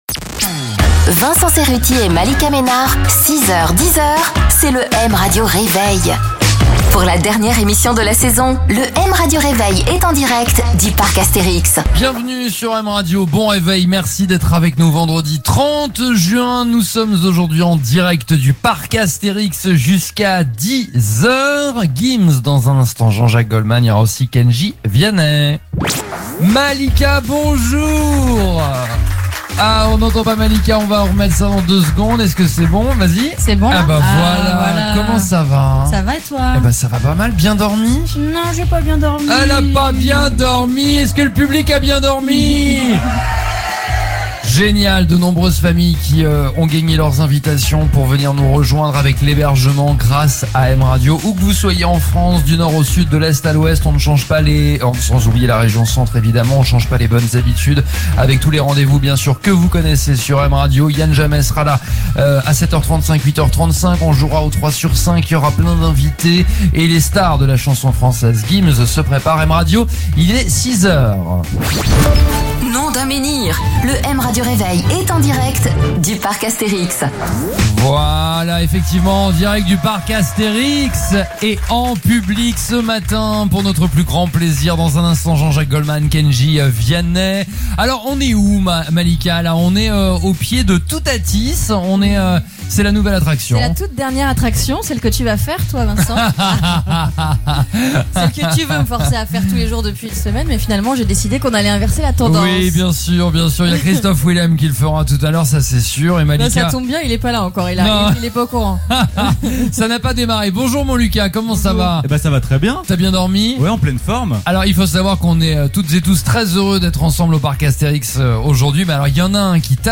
Podcast : L’intégrale du M Radio Réveil en direct du parc Astérix - 30 juin